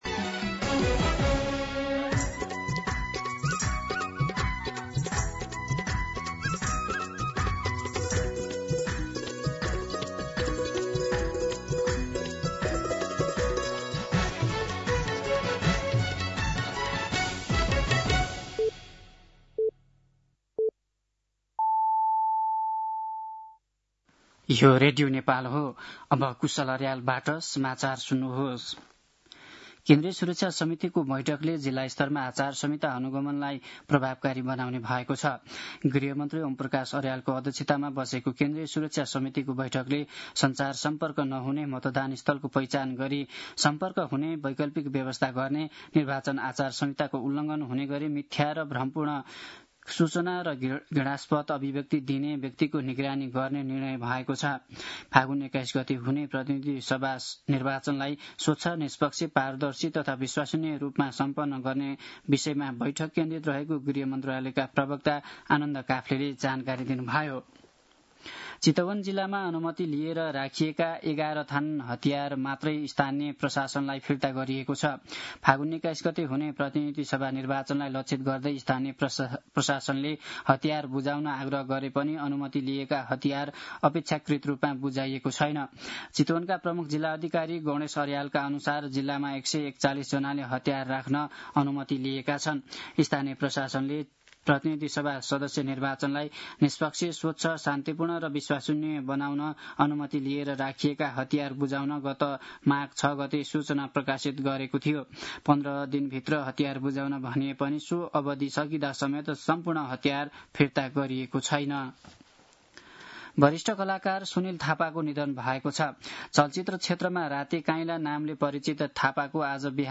दिउँसो १ बजेको नेपाली समाचार : २४ माघ , २०८२
1-pm-Nepali-News-1.mp3